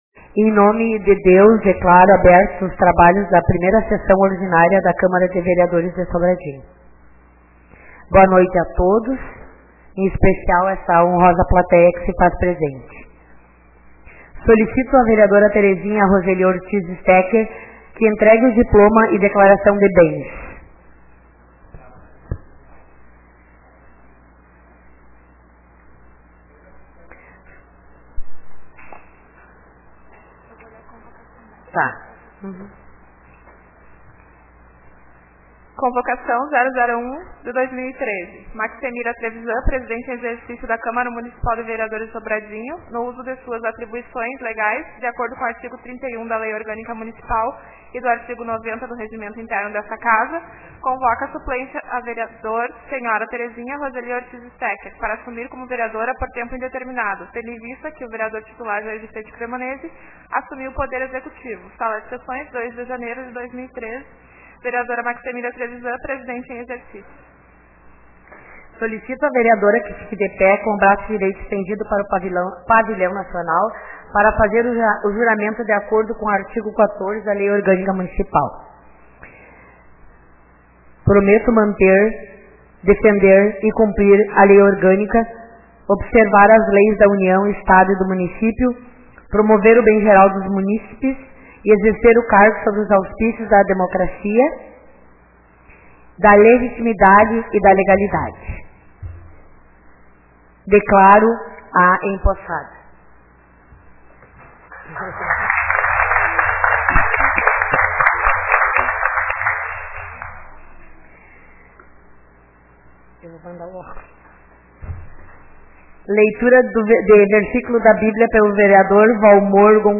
1ª Sessão Ordinária